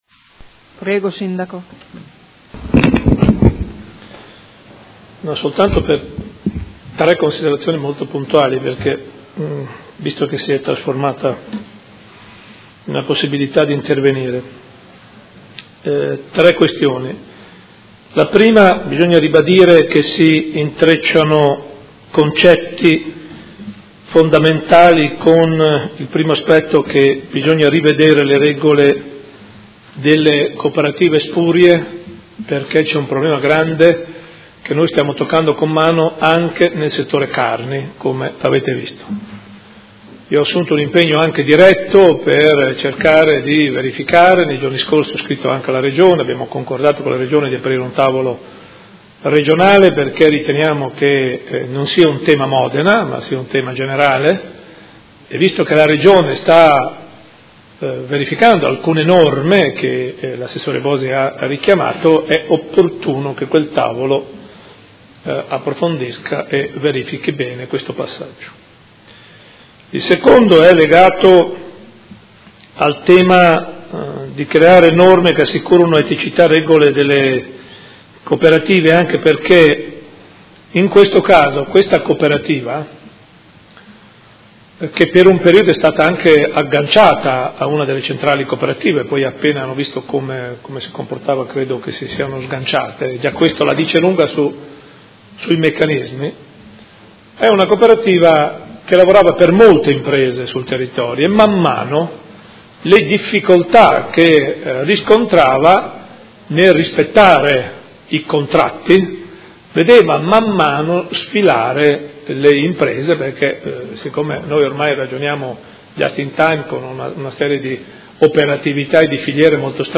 Seduta del 10/03/2016. Dibattito su interrogazione del Gruppo Consiliare Movimento 5 Stelle avente per oggetto: Cooperativa La Carpigiana Service Primo firmatario Consigliere Fantoni
Sindaco